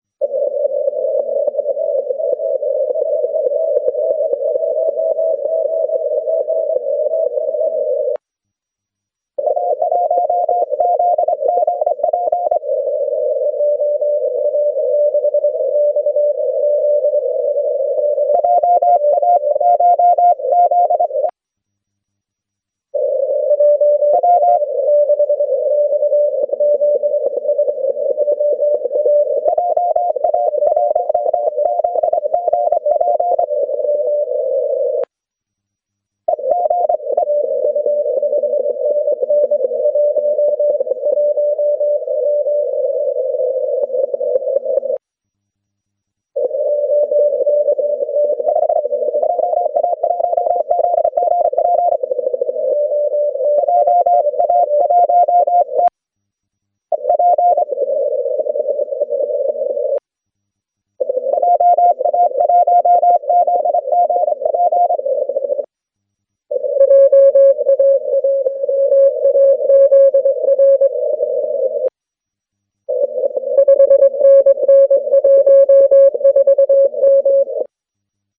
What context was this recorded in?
One of the recordings of QSOs on 3R5 MHz CW at All JA Contest 2014, Apr. 27, around 1851 LMT. It was just after the sunset on my QTH.